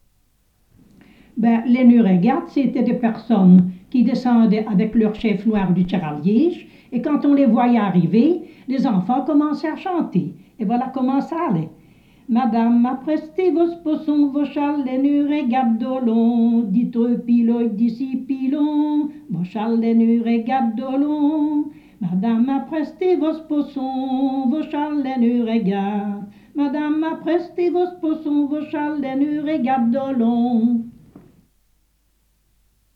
Type : chanson narrative ou de divertissement | Date : 1 juillet 1972